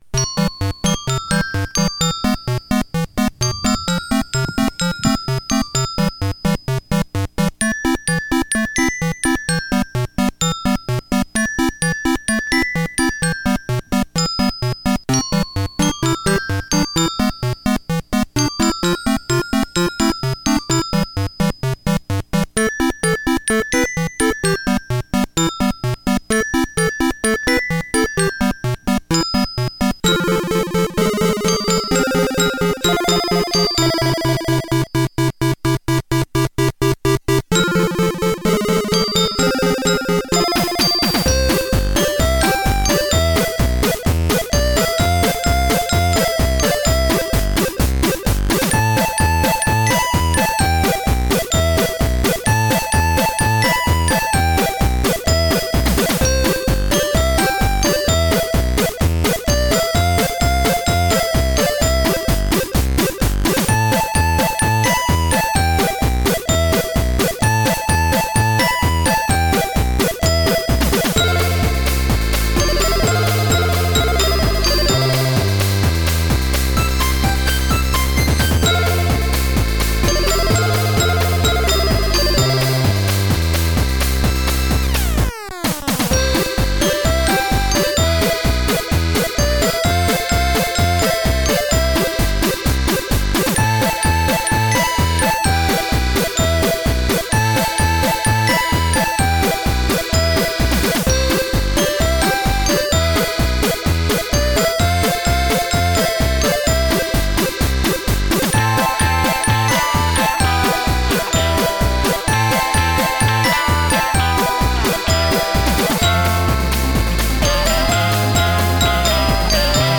reden live Unsinn über die Machbar, die Pandemie, die BVG und Urteile, die was mit digital zum tun haben